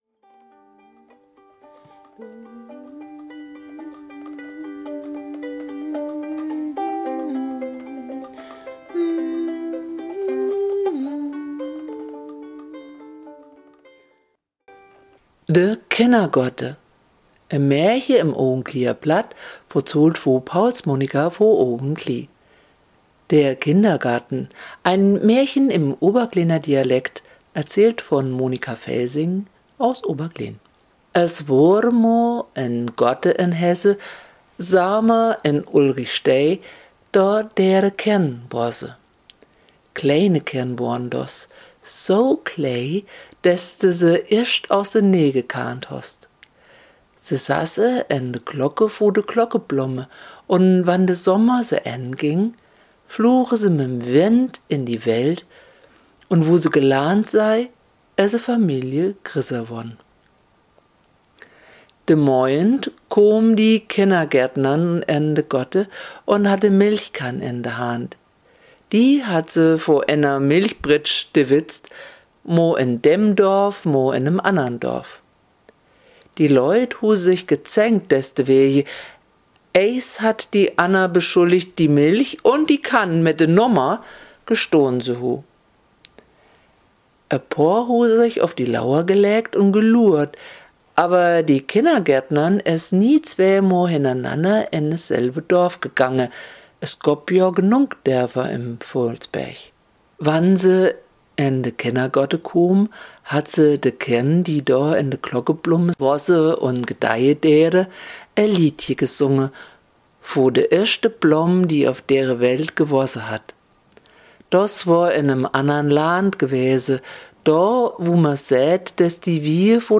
Die Melodie ist improvisiert.